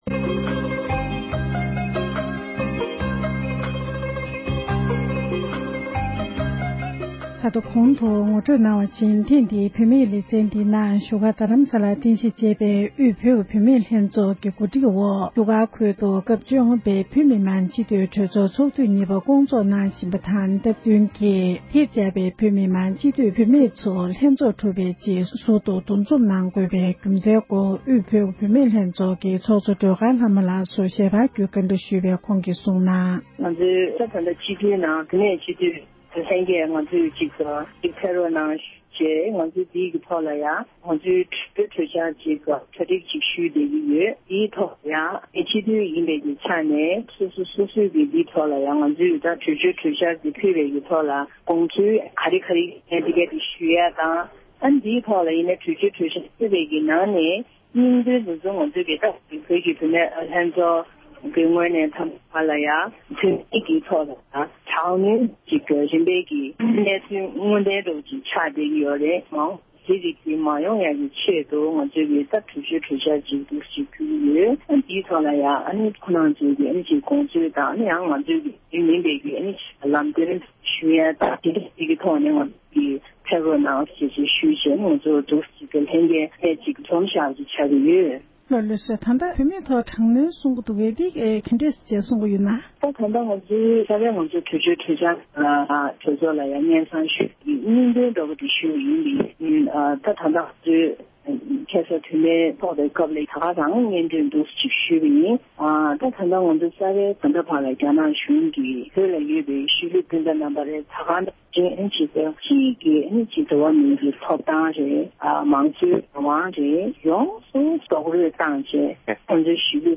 འབྲེལ་ཡོད་མི་སྣར་གནས་འདྲི་ཞུས་པ་ཞིག་གསན་རོགས་ཞུ༎